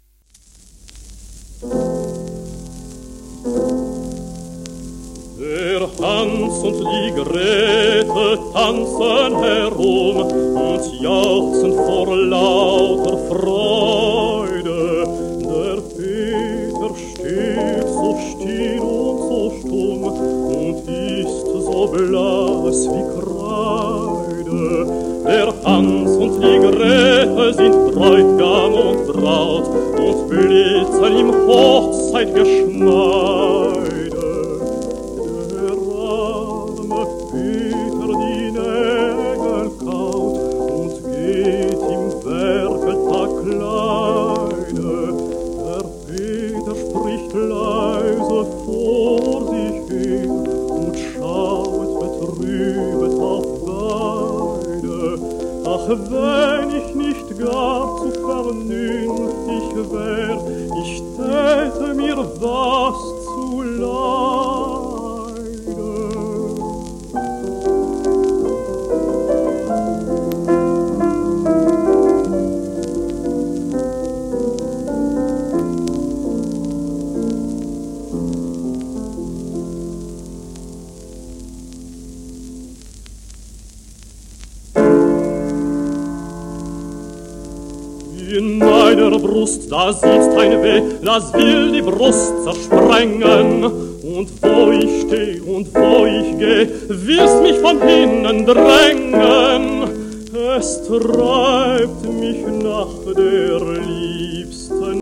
ジェラール・スゼー(Br:1918-2004)
w/ジャクリーヌ・ボノー(p)
1949年頃録音